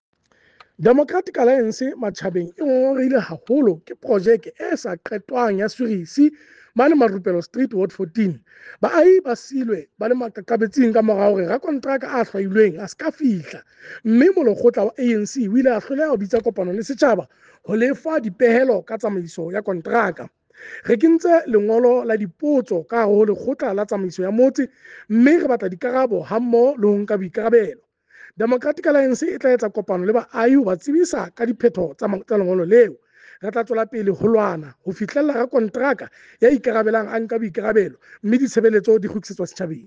Sesotho soundbite by Cllr Kabelo Moreeng.
incomplete-sewer-project-in-Ward-14-SOTHO-.mp3